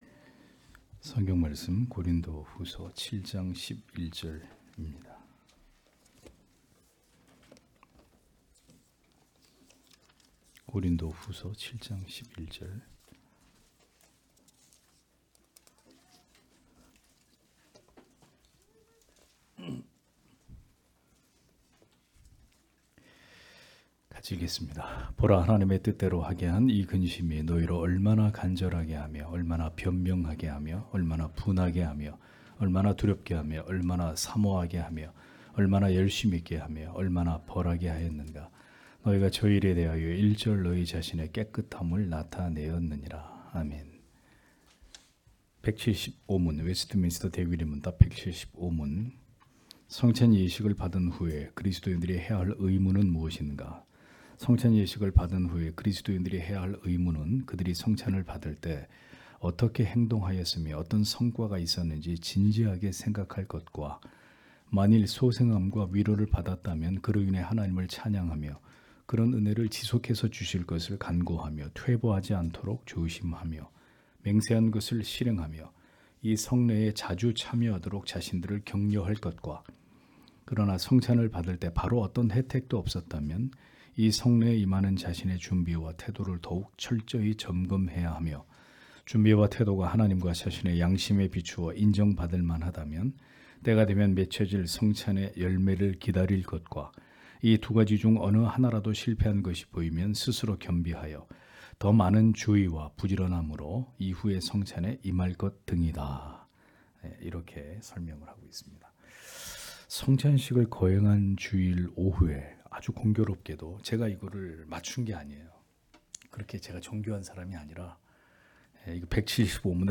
주일오후예배 - [웨스트민스터 대요리문답 해설 175]175문) 성찬의 성례를 받은 후에 그리스도인들의 의무는 어떠한 것인가? (고후7장 11절)
* 설교 파일을 다운 받으시려면 아래 설교 제목을 클릭해서 다운 받으시면 됩니다.